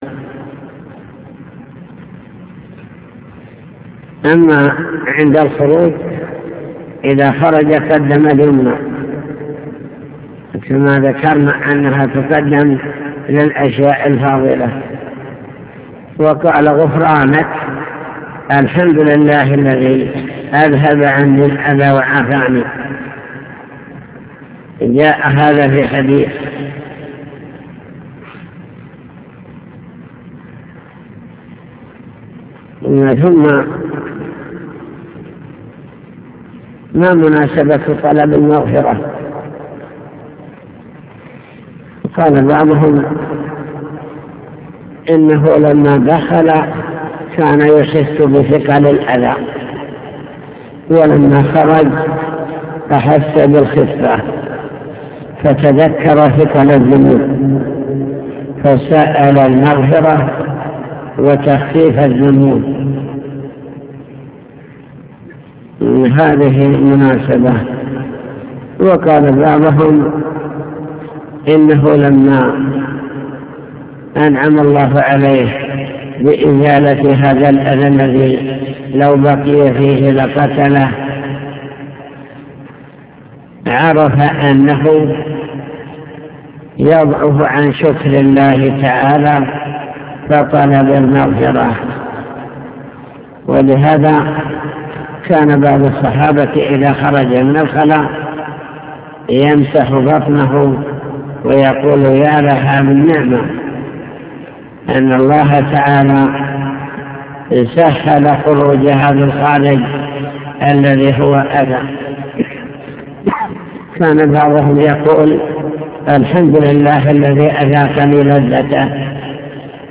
المكتبة الصوتية  تسجيلات - كتب  شرح كتاب دليل الطالب لنيل المطالب كتاب الطهارة باب الاستنجاء آداب التخلي